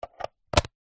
Звуки розетки, выключателей
Звук зарядника в розетке